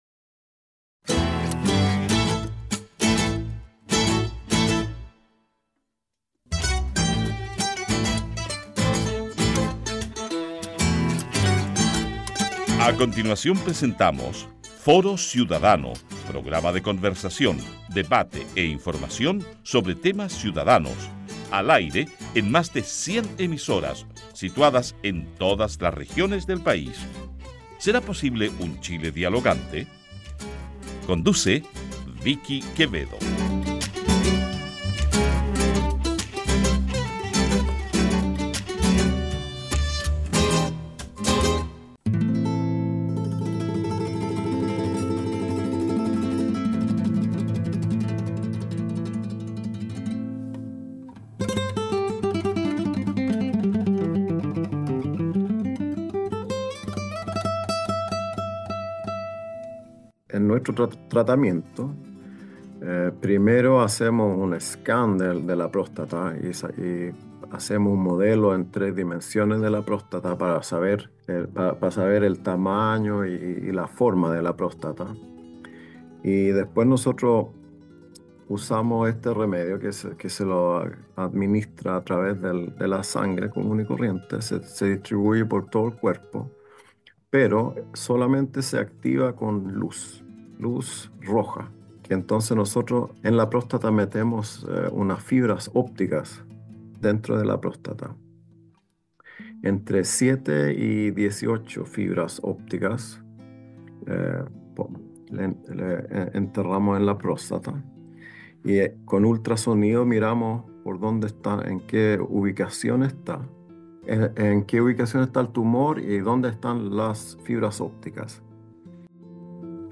Las estadísticas indican que alrededor de un 13% de los varones pueden tener un diagnóstico de cáncer de próstata. Conversamos con un científico chileno que trabaja en Suecia, forma parte de un espacio que investiga en un tratamiento para este tipo de tumores.